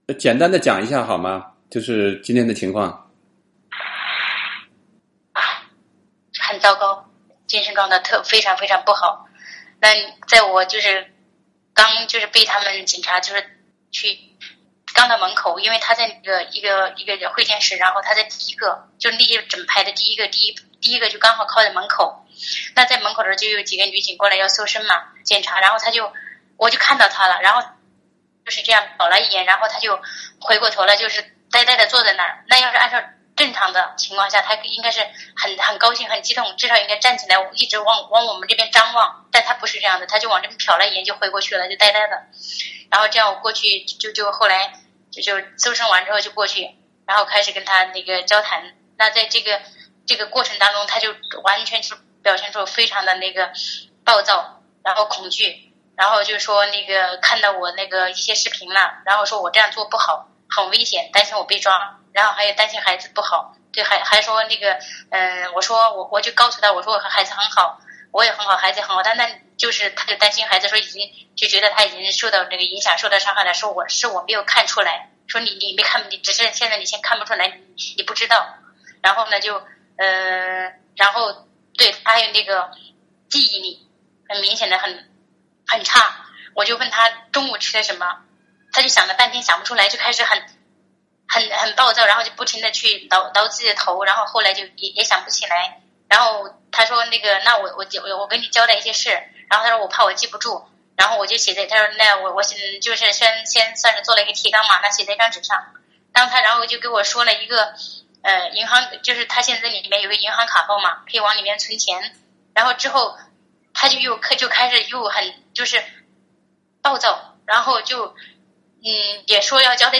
（根据电话录音整理，受访者言论不代表美国之音）